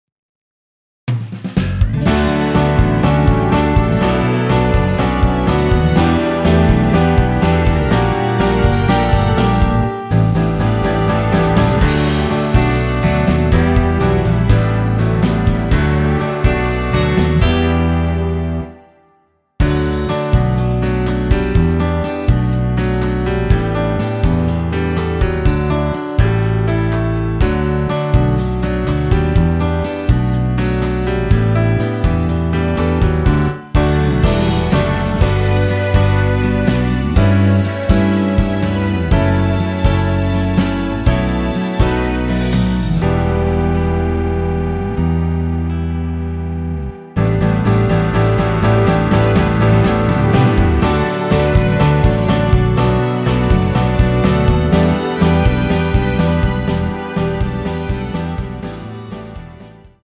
원키에서(-1)내린 MR 입니다.
앞부분30초, 뒷부분30초씩 편집해서 올려 드리고 있습니다.